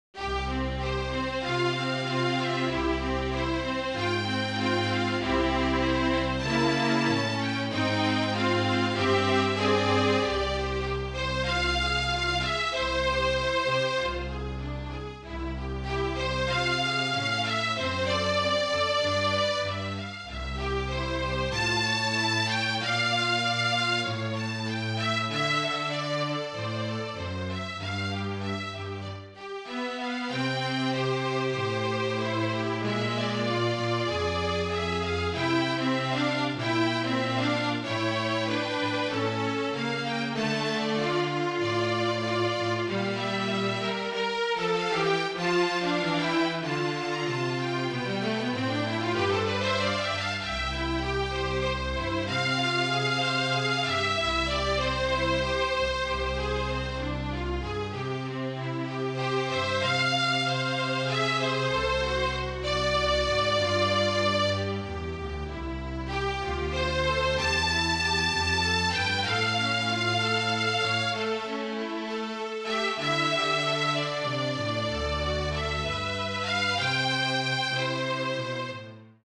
FLUTE TRIO
Flute, Violin and Cello (or Two Violins and Cello)